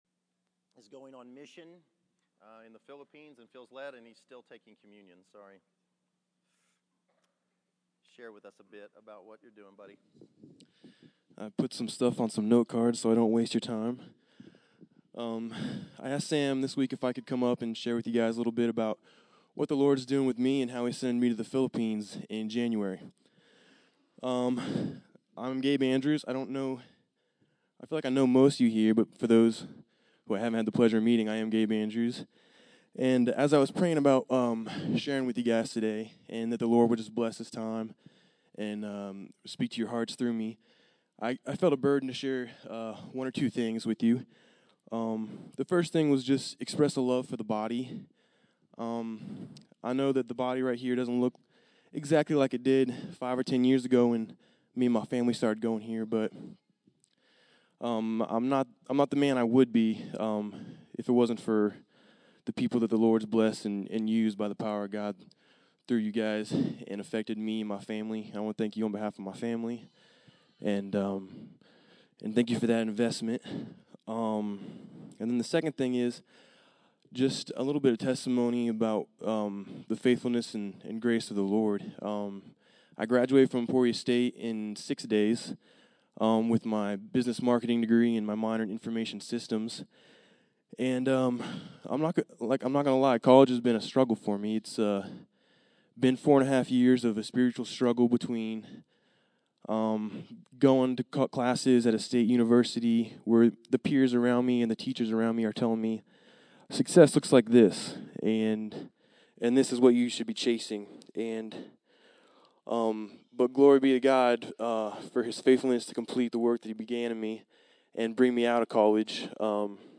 December 07, 2014      Category: Testimonies      |      Location: El Dorado